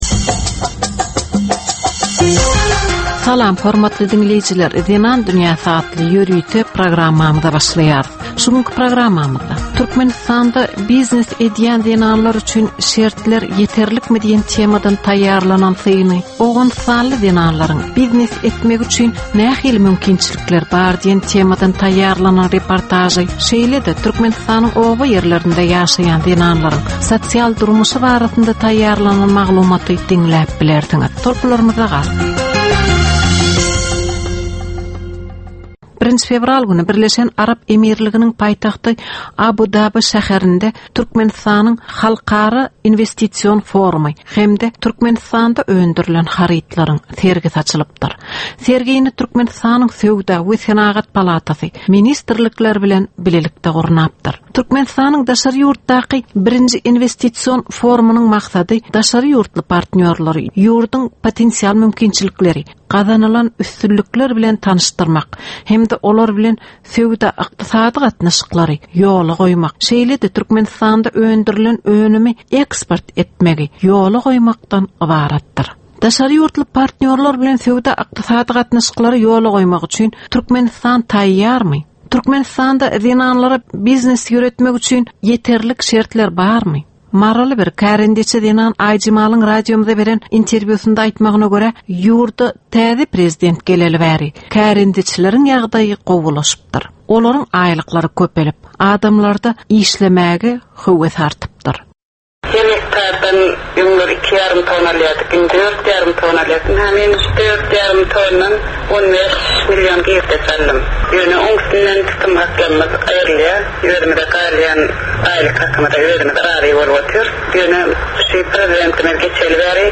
Türkmen we halkara aýal-gyzlarynyň durmuşyna degişli derwaýys meselelere we täzeliklere bagyşlanylyp taýýarlanylýan 15 minutlyk ýörite gepleşik. Bu gepleşikde aýal-gyzlaryn durmuşyna degişli maglumatlar, synlar, bu meseleler boýunça synçylaryň we bilermenleriň pikirleri, teklipleri we diskussiýalary berilýär.